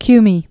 (kymē)